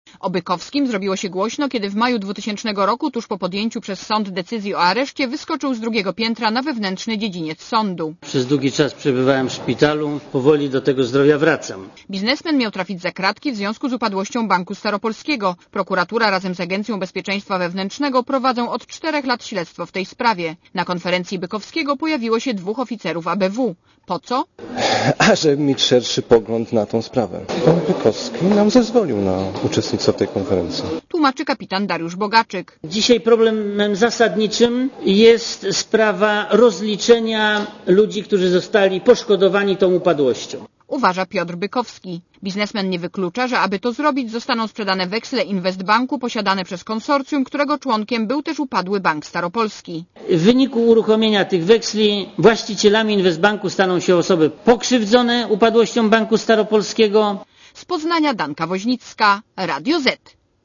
Posłuchaj relacji reporterki Radia Zet (235 KB)